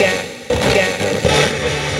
120BPMRAD3-R.wav